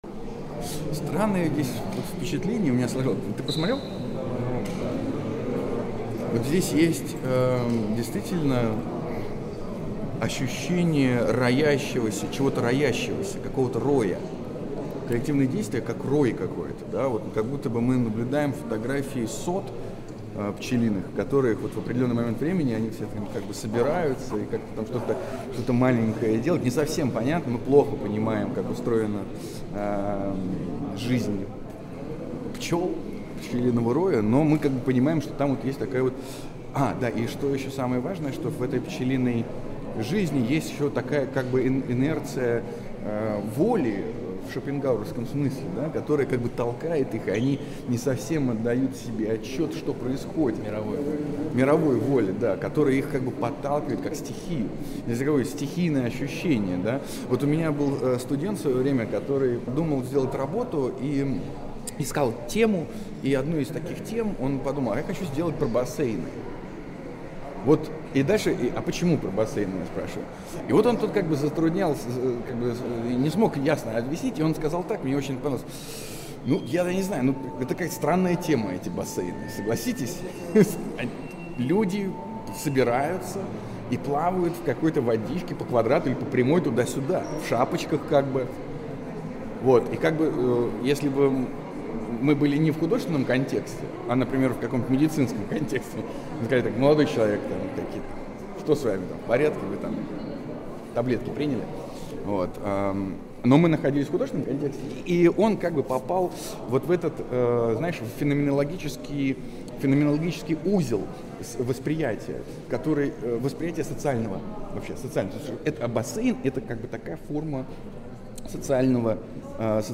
Админы канала «Суверенное Искусство» вспоминают всё самое новое в истории западного человечества и открывают портал в MMXXV год.